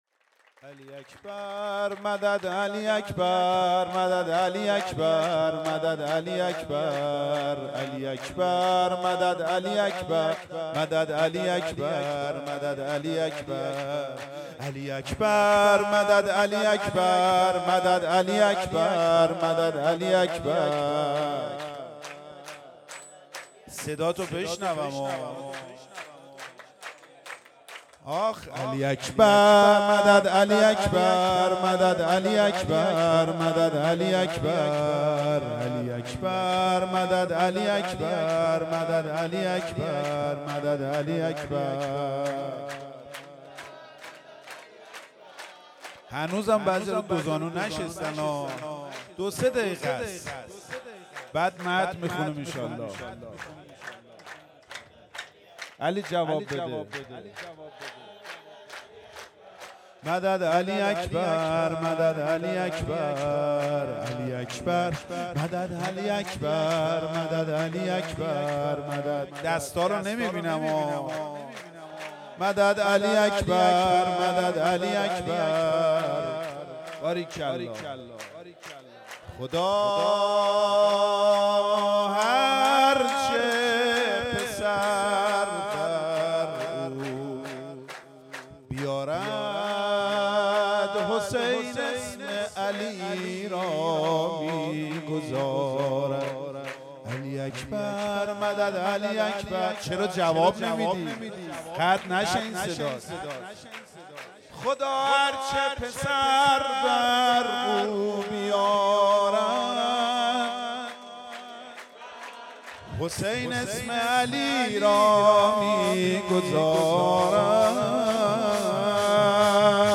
شور
جشن میلاد حضرت علی اکبر(ع)1398